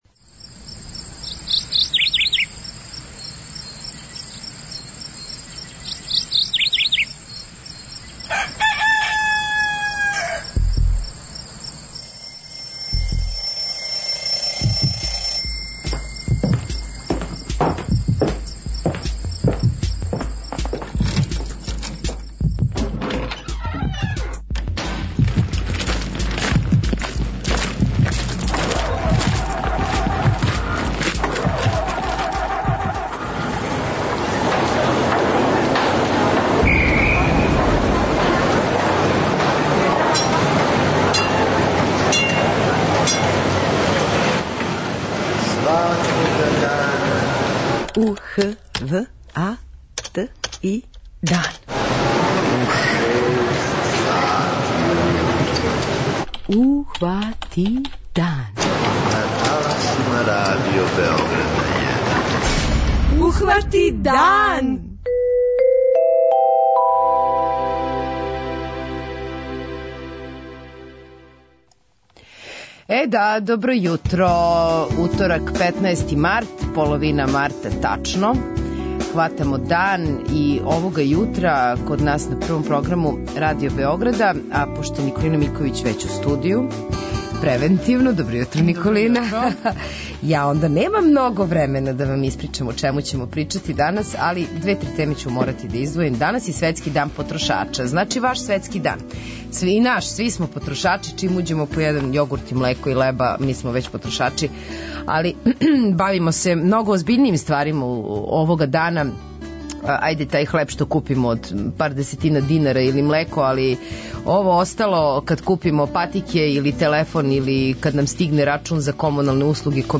У Питању јутра и слушаоци ће имати прилику да испричају своја искуства са трговцима.
преузми : 43.15 MB Ухвати дан Autor: Група аутора Јутарњи програм Радио Београда 1!